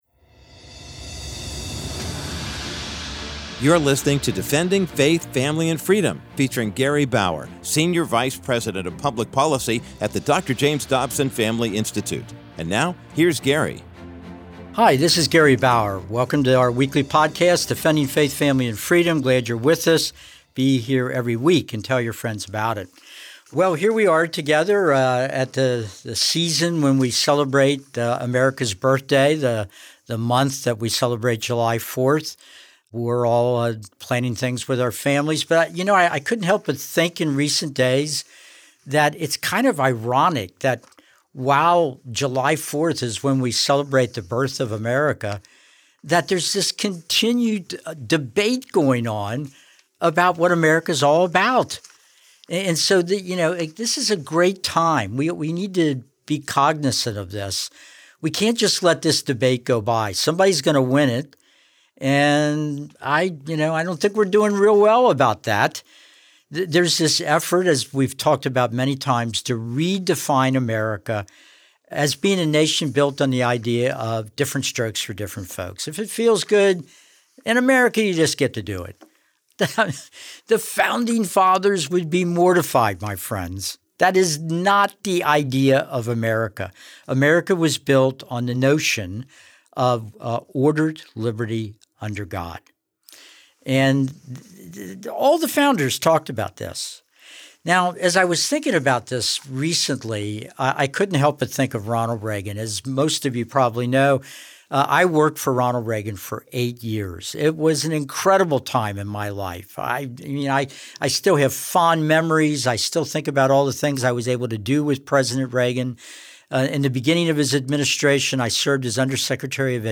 In this week's episode of Defending Faith, Family, and Freedom, Gary Bauer shares that radical secularists (today's neo-Marxists) have been attempting to rewrite America's founding, to erase religion (specifically, Christianity) for a very long time. Bauer plays clips from a speech delivered by President Ronald Reagan held at a Dallas, Texas, prayer breakfast in 1984.
Shredding them by root and branch, the President brought the 17,000 attendees to thunderous ovations again and again.